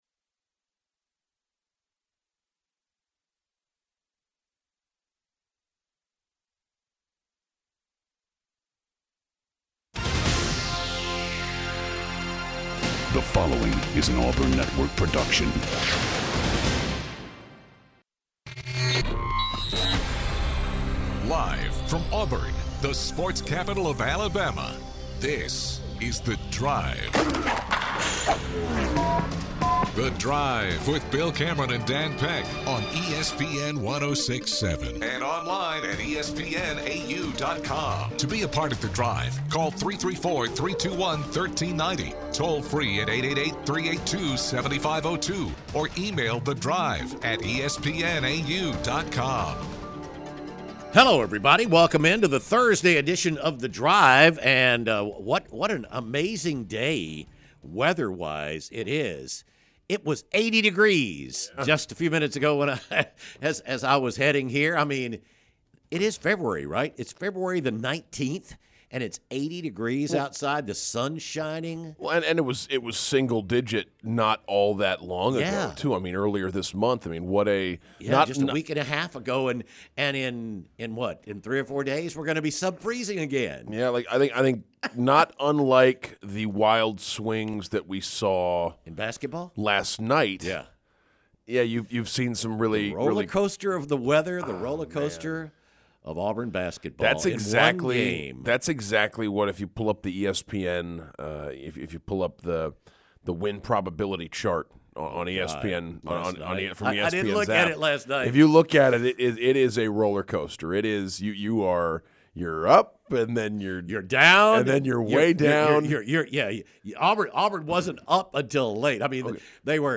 take questions from callers about what went wrong and where the team can go from here